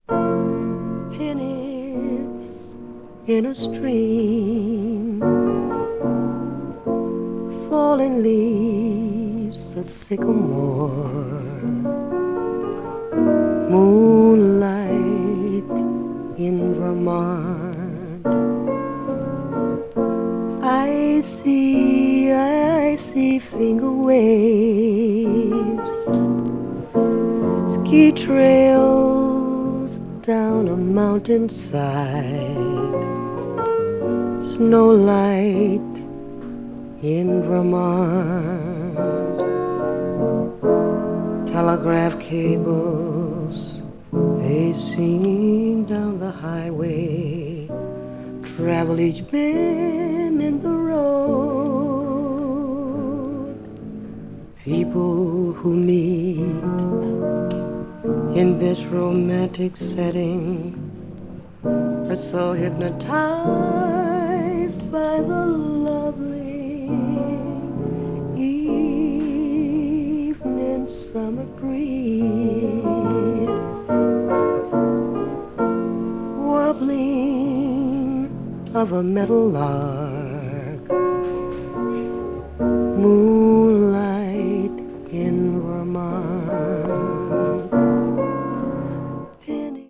blues jazz   jazz standard   jazz vocal